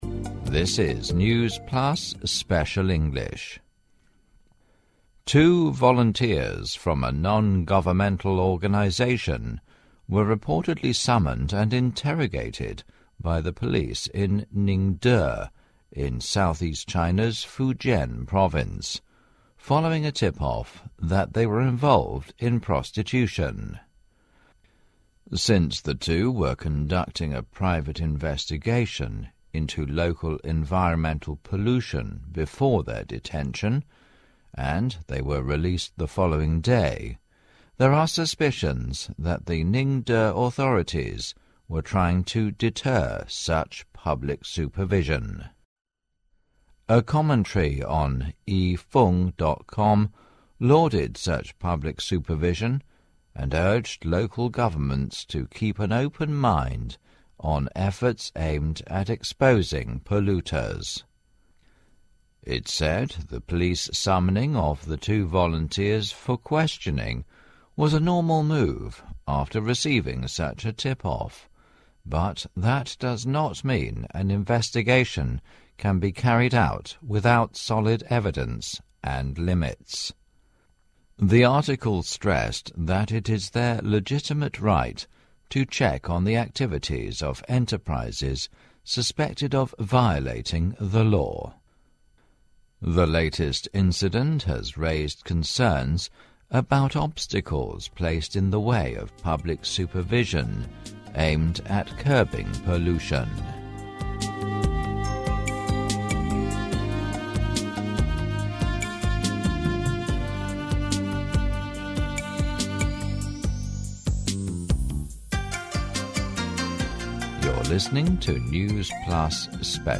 News Plus慢速英语:环保人士赴福建宁德查污染被指卖淫嫖娼 邓亚萍任法大兼职教授惹争议